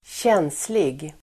Uttal: [²tj'en:slig]